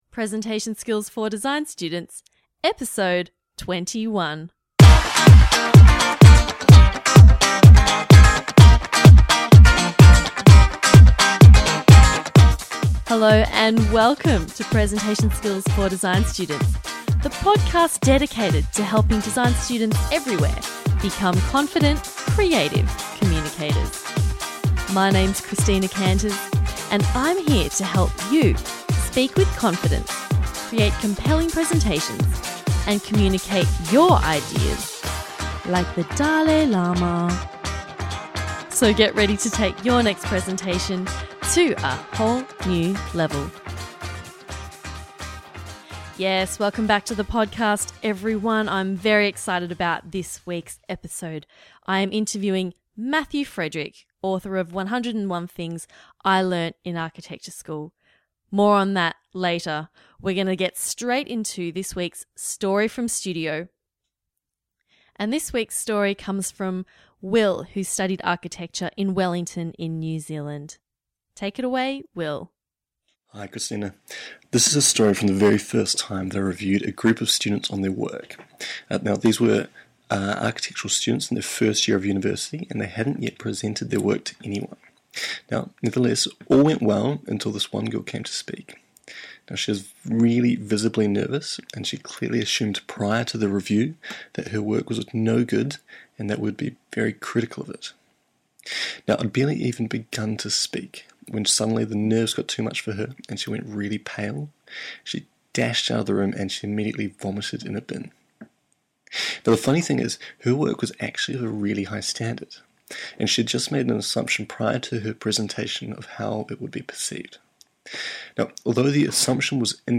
Episode 21: How to Manage Your Ego…and Other Things I Learned in Architecture School – Interview with Matthew Frederick